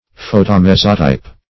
Search Result for " photomezzotype" : The Collaborative International Dictionary of English v.0.48: Photomezzotype \Pho`to*mez"zo*type\, n. [Photo- + It. mezzo middle, half + -type.] A photomechanical process similar to collotype.